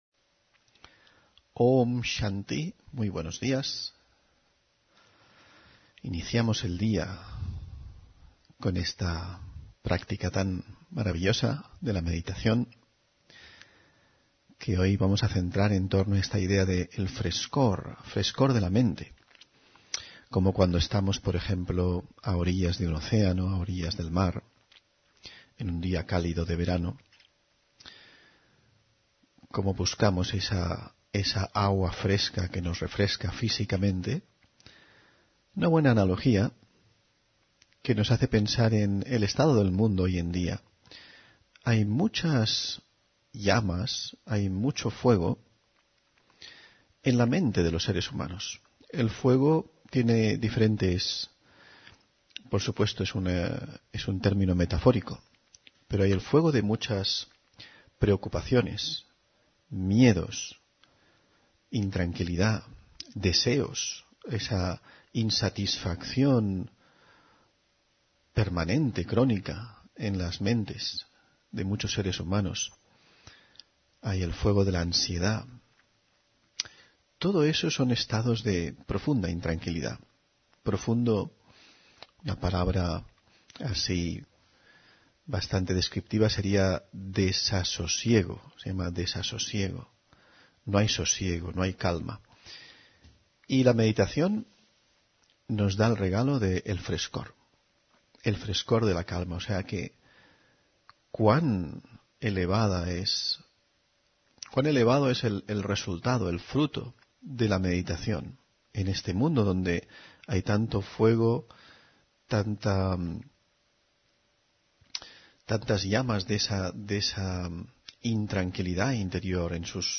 Meditación y conferencia: Sanar y proteger la tierra (22 Abril 2024)